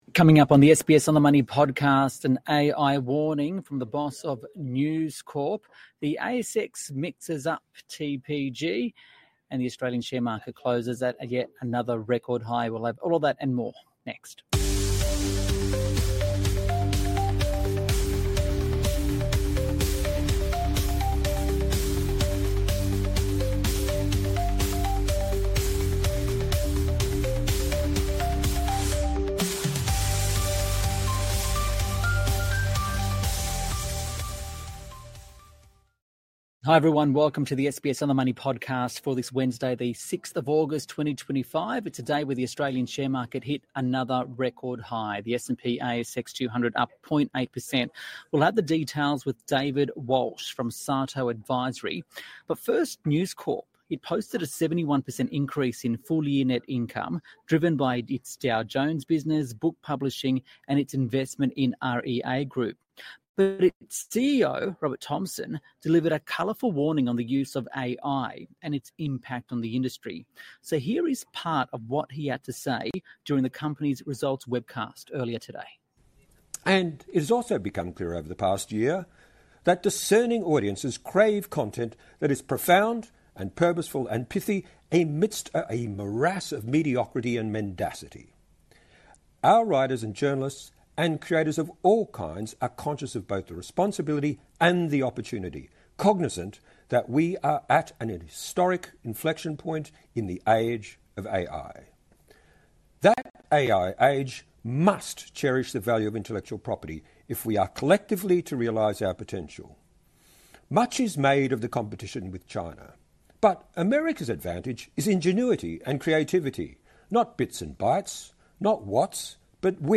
Plus, hear from News Corp CEO Robert Thomson as he delivers an AI warning at the company's results announcement.